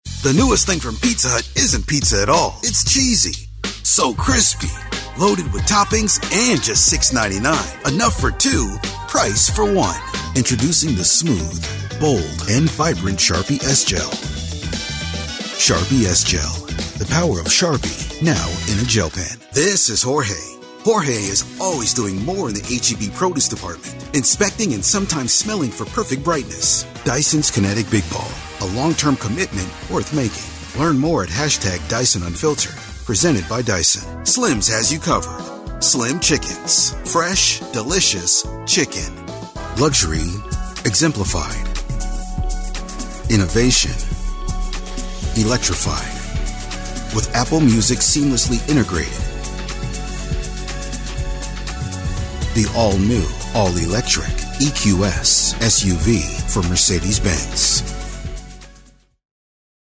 Home Studio, Professional audio.
middle west
Sprechprobe: Werbung (Muttersprache):
Commercials